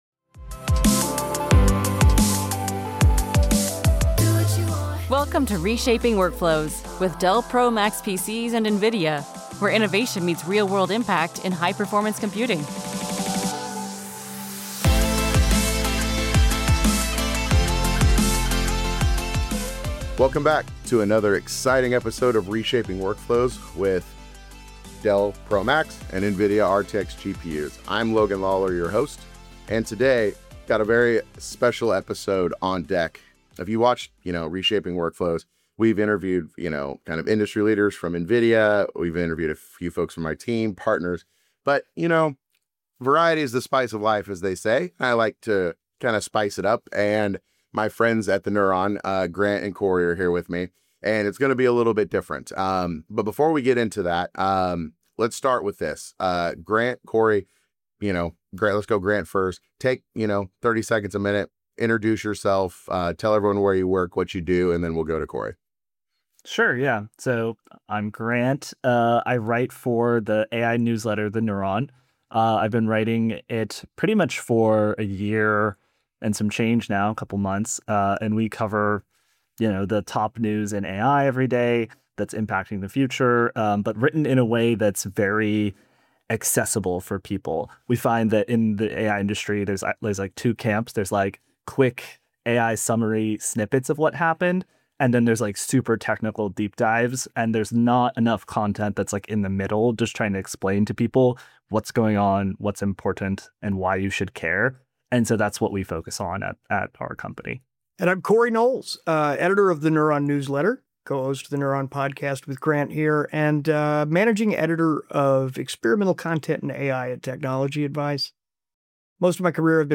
The trio addresses the challenges and opportunities of AI in education, work, and creative fields, offering practical tips for maximizing the benefits of reasoning models, whether writing, coding, or running benchmarks.